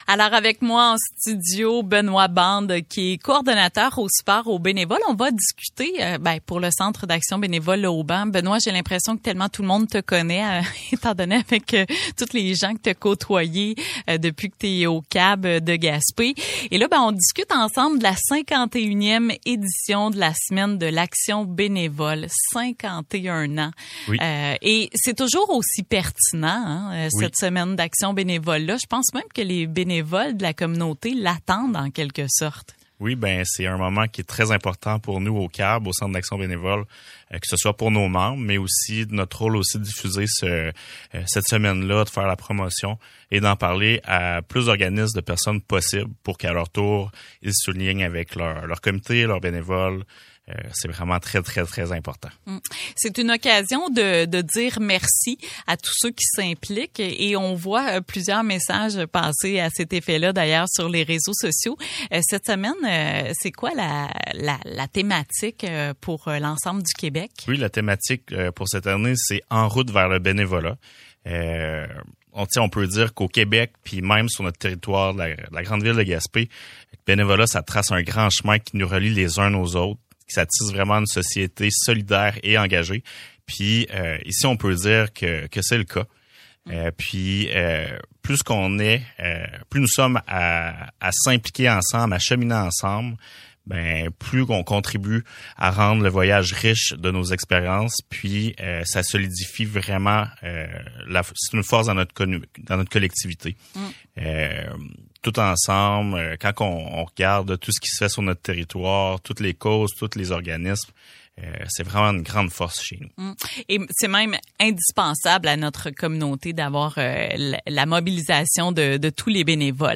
51e édition de la Semaine de l’action bénévole - Radio-Gaspésie